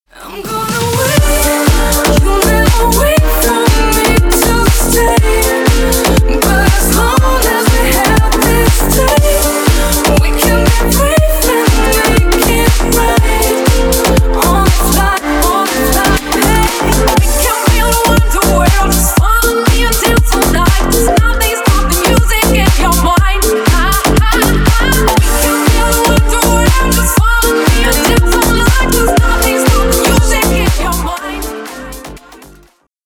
Ремикс # Поп Музыка